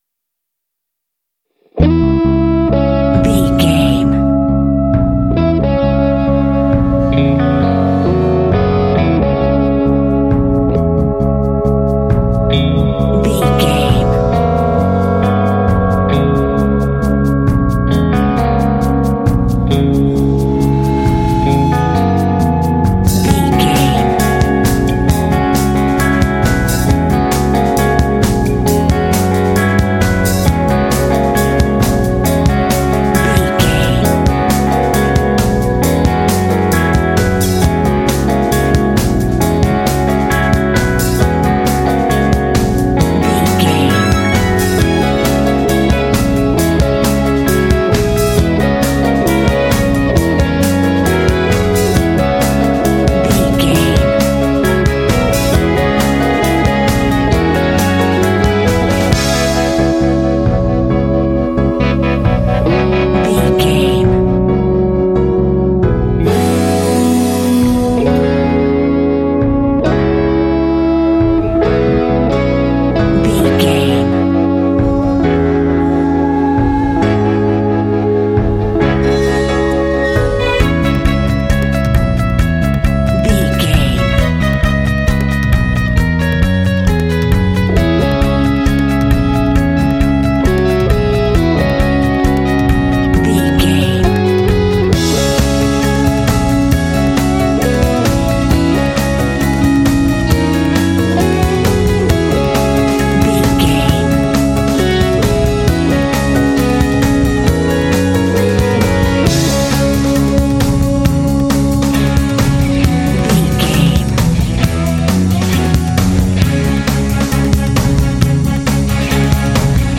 Epic / Action
Aeolian/Minor
C#
dramatic
foreboding
tension
electric guitar
synthesiser
percussion
drums
bass guitar
piano
strings
cinematic
orchestral
film score